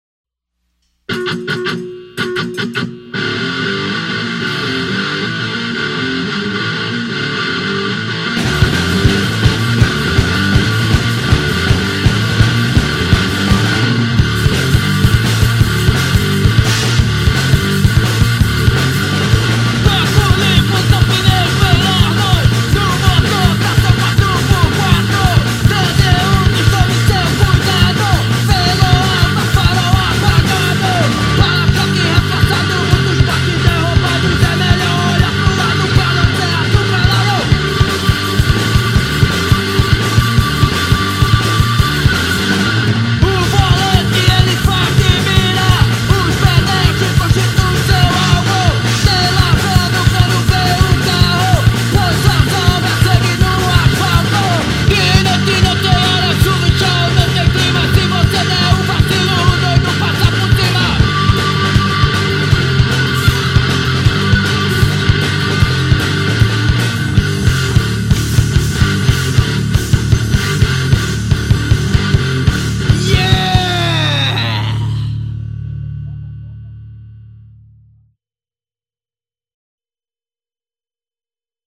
Som direto, sem excesso, com peso e intenção.